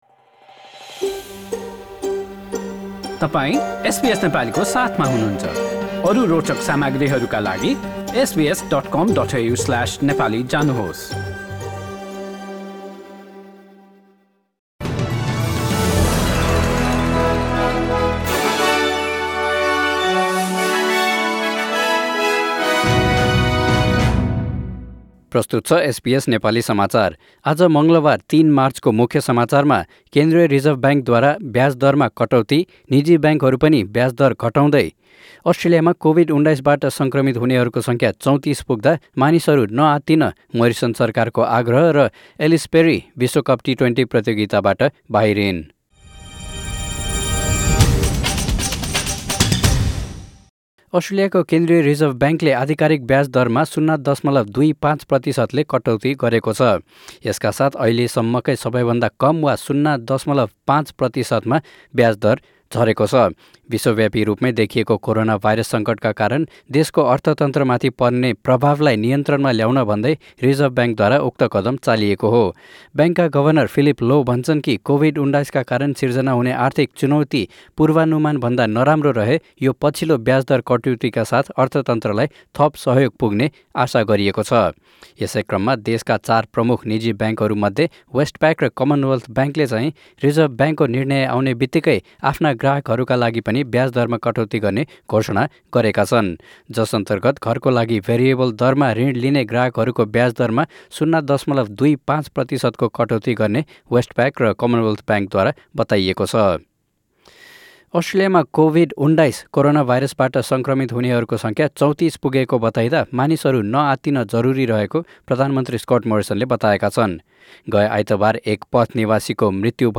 एसबीएस नेपाली अस्ट्रेलिया समाचार: मङ्गलवार ३ मार्च २०२०